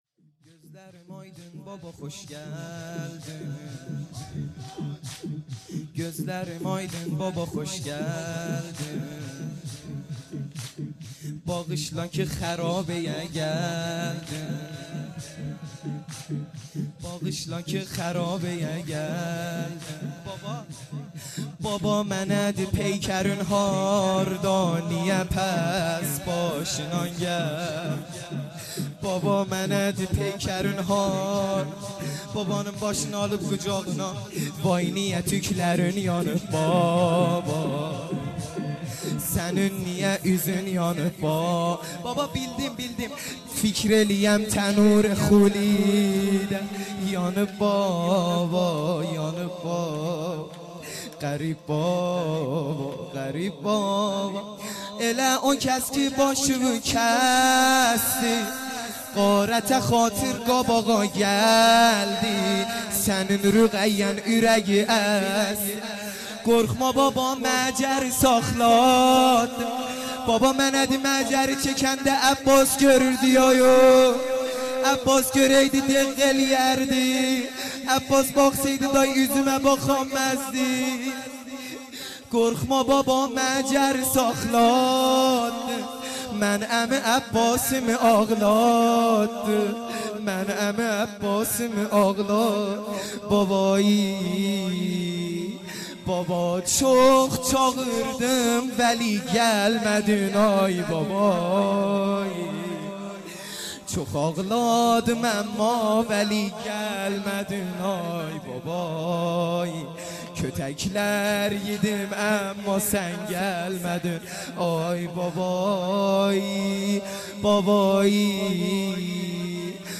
لطمه زنی